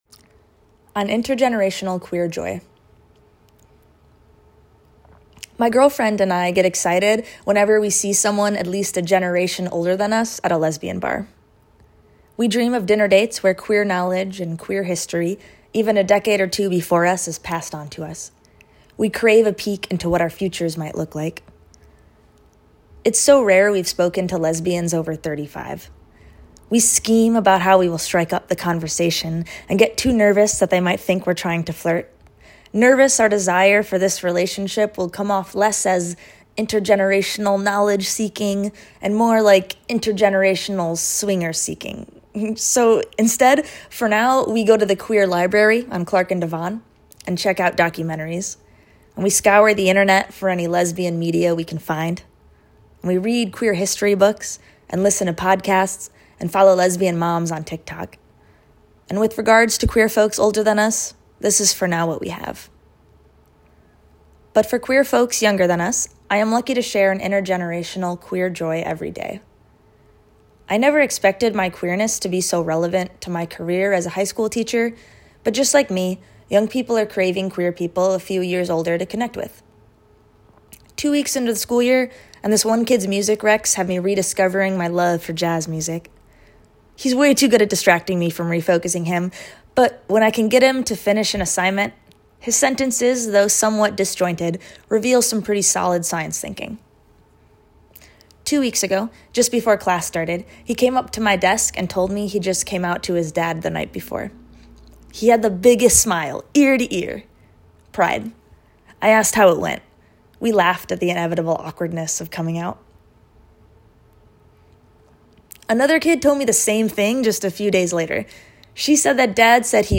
Here the poem read by the author: